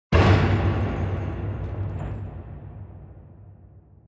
impact-2.ogg